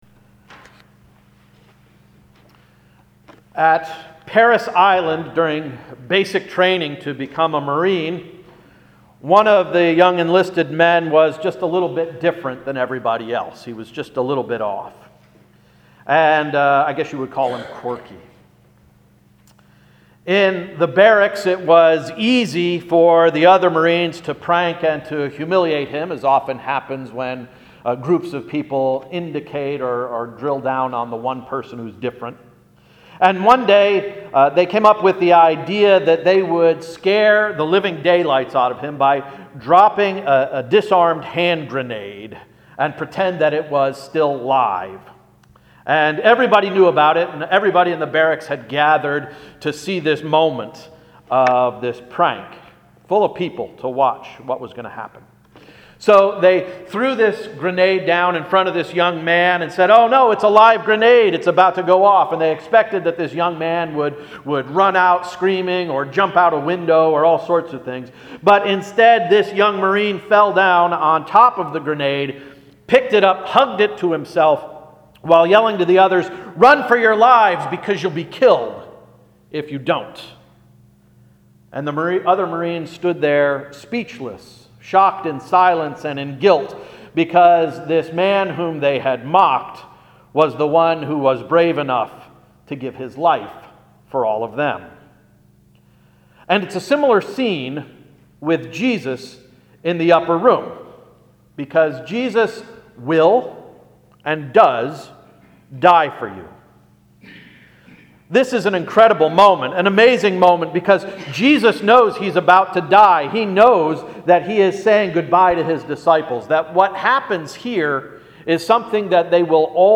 Sermon of March 11, 2017–“Washing Away Barriers”